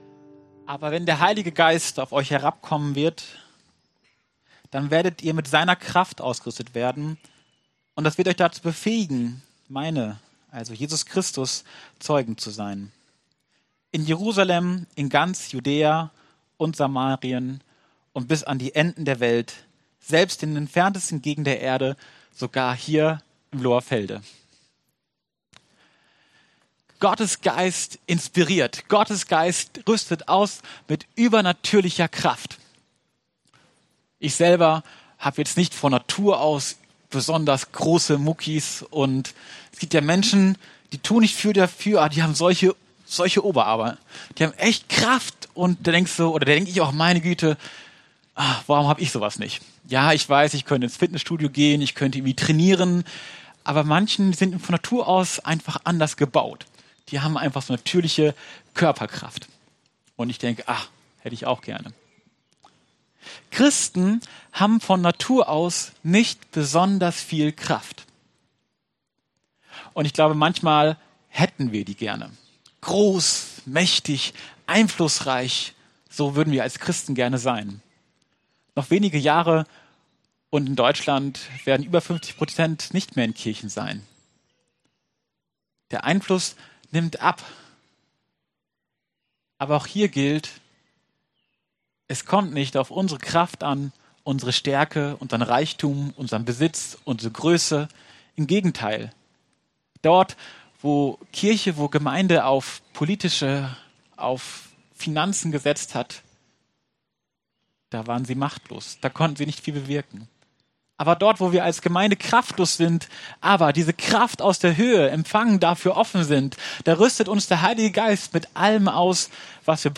Entspanntheit und Einsatz Prediger(-in)